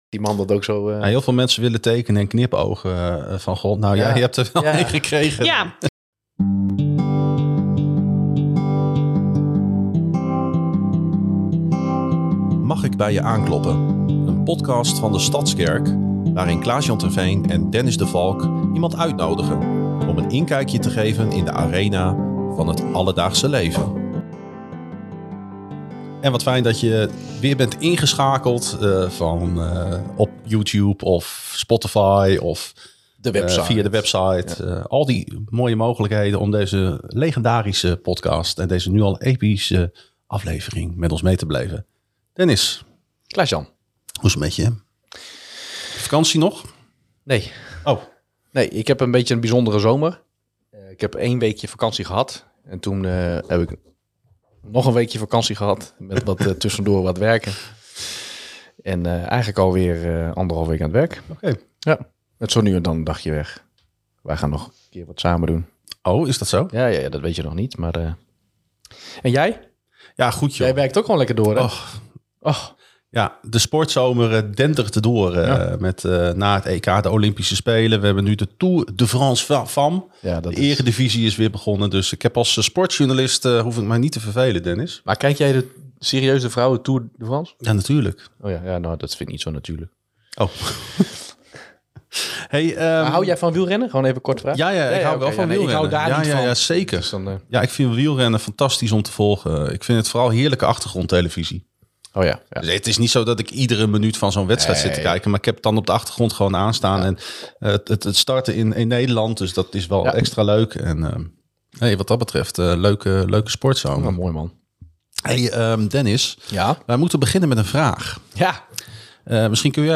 De gastheren kloppen aan bij gemeenteleden en gasten om samen in gesprek te gaan.